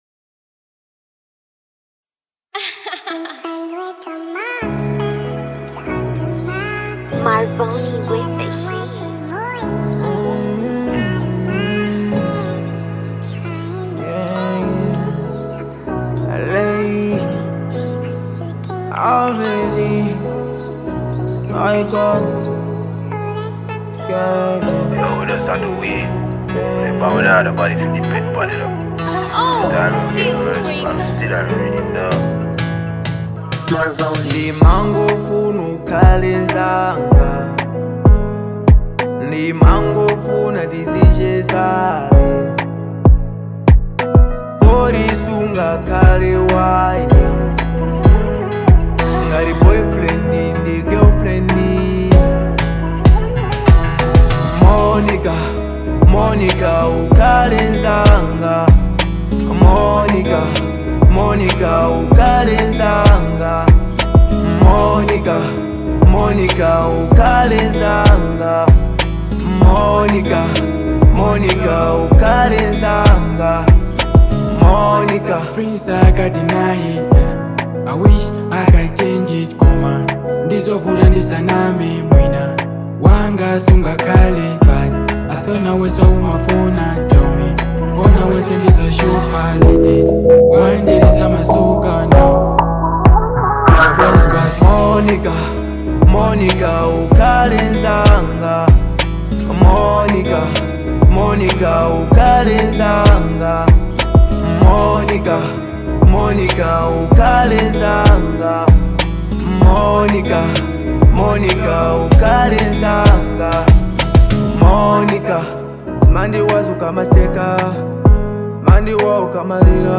Afro-Beat